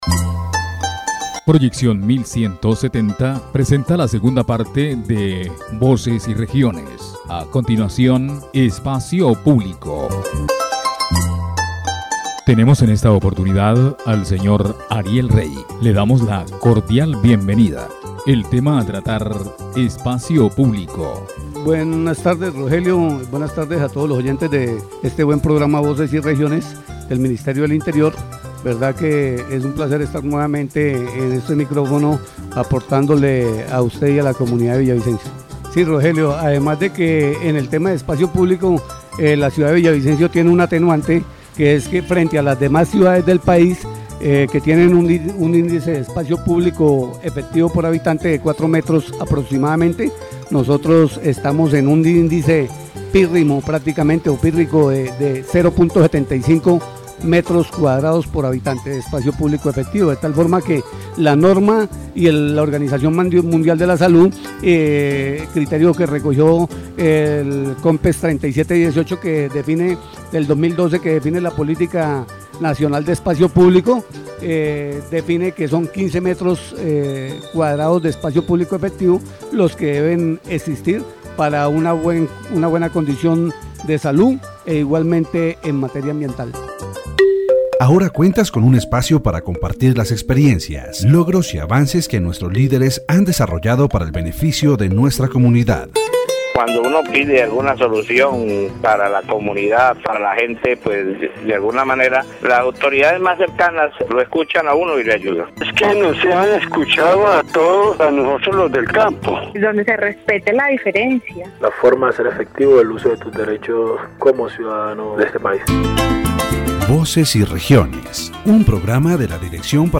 The radio program "Voces y Regiones" presents a critical overview of the situation of public space in Villavicencio, Colombia. It reveals underlying problems related to corruption, lack of planning and violation of citizens' rights. Informal vendors are the most affected by these policies and are seeking a fair and equitable solution.